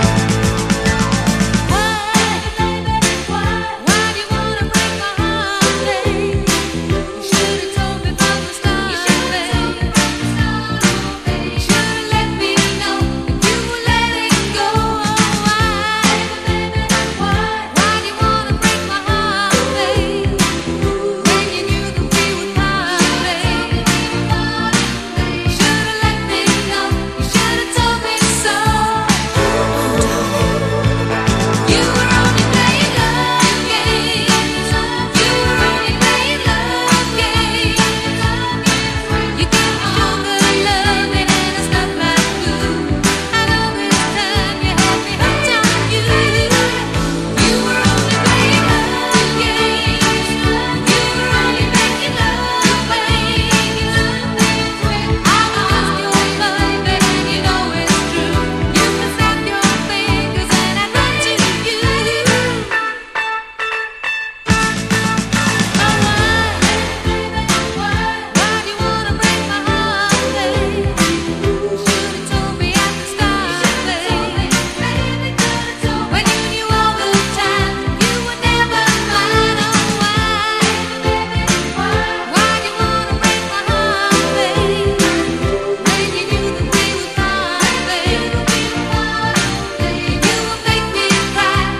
60’Sモータウン・サウンドをオマージュした眩しい80’Sガールポップ！